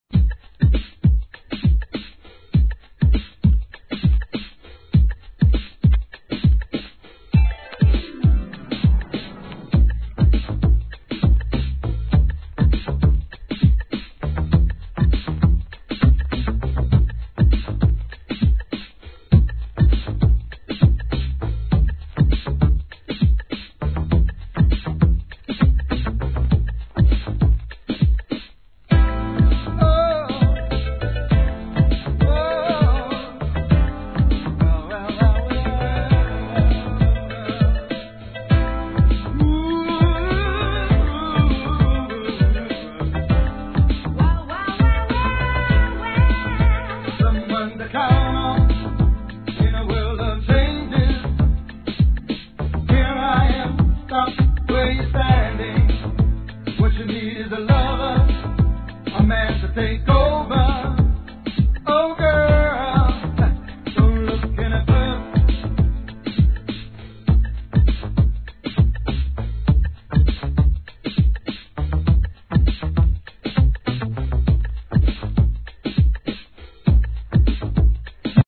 1990年、HOUSE〜HIP HOUSE, RAP〜R&Bとフル･ボリュームでのDANCEコンピ!!!